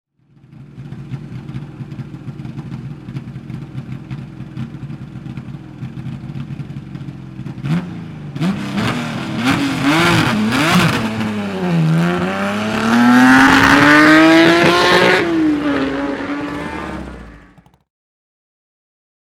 BMW 635 CSi Gruppe 2 (1981) - Start am Bergrennen Arosa ClassicCar 2013